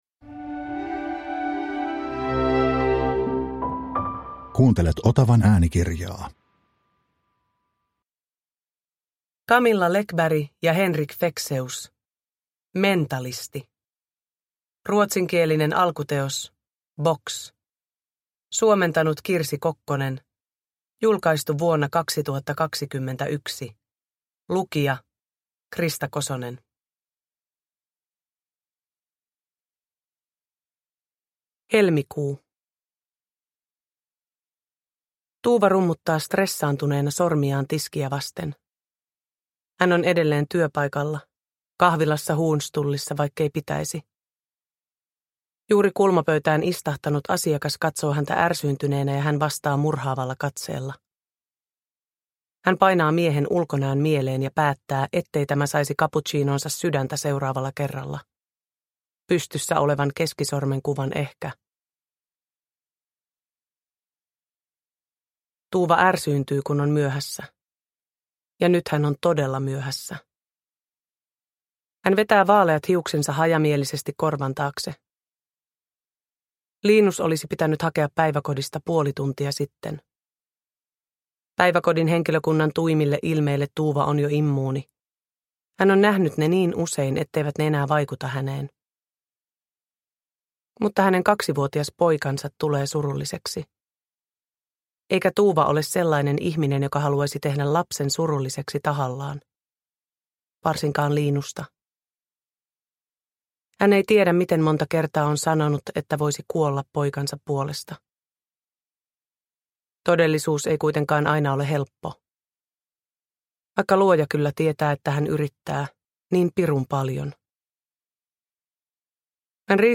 Mentalisti – Ljudbok – Laddas ner
Uppläsare: Krista Kosonen